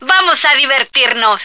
flak_m/sounds/female1/est/F1letsrock.ogg at 602a89cc682bb6abb8a4c4c5544b4943a46f4bd3